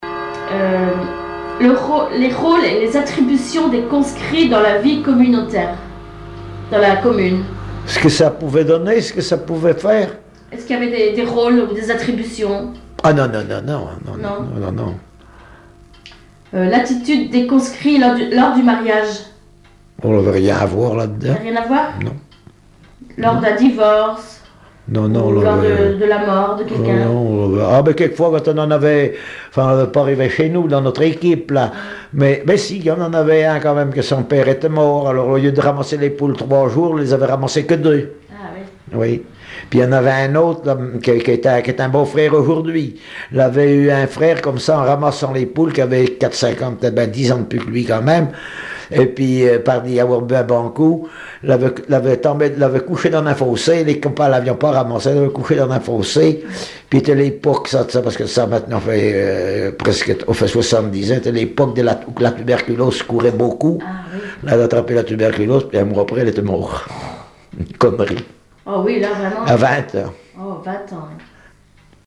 Enquête Arexcpo en Vendée-C.C. Essarts
Catégorie Témoignage